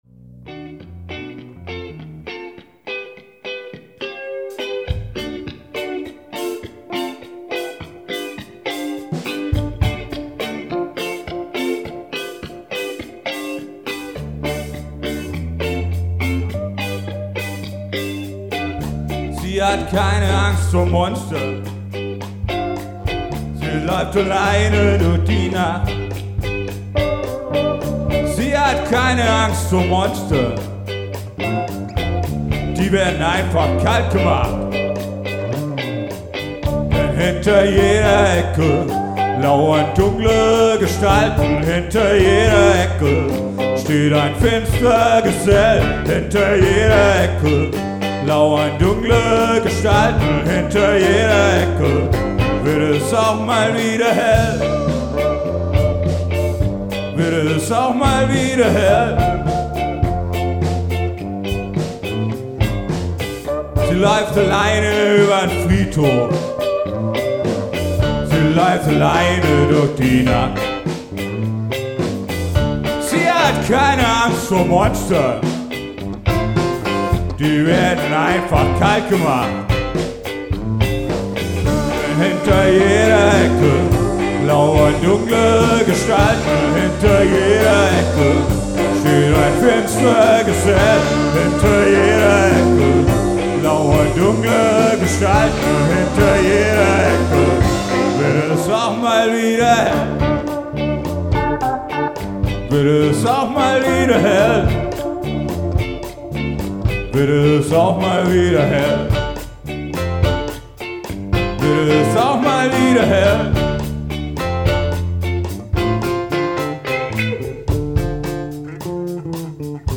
Stimmungs Ska